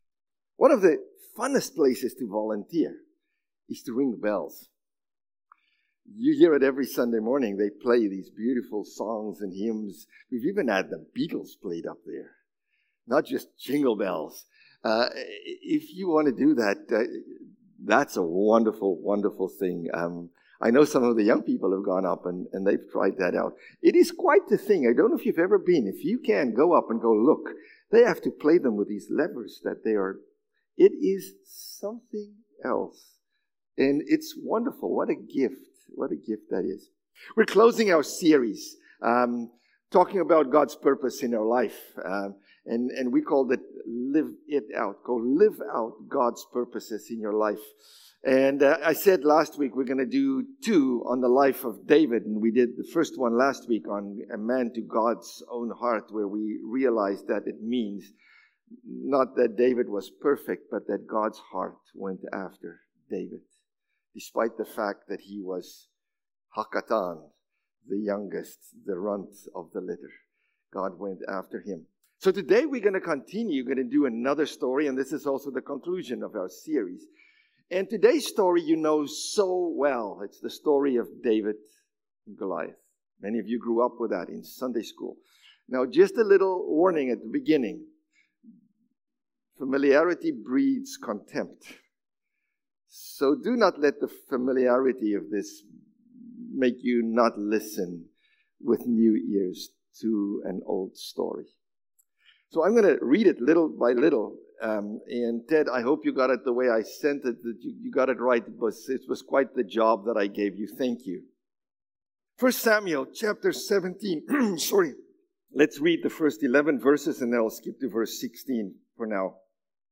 September-15-Sermon.mp3